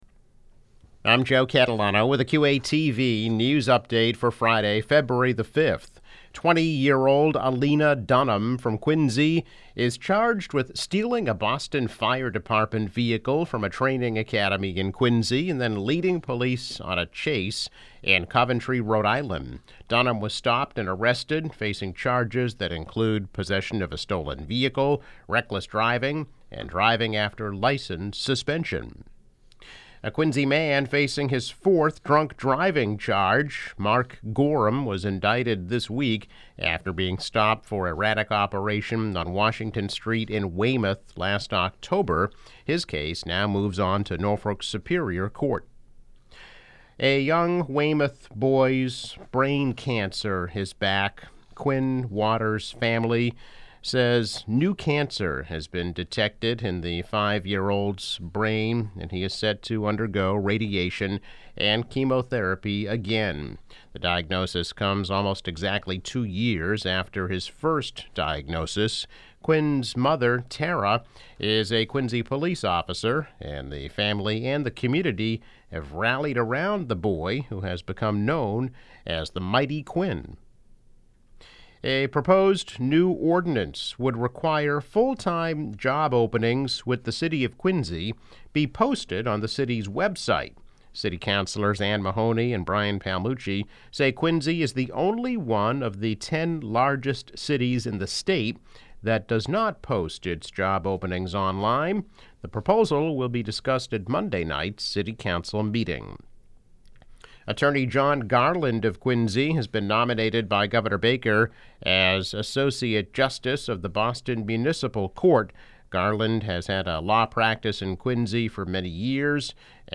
News Update - February 5, 2021